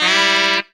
HARM RIFF 8.wav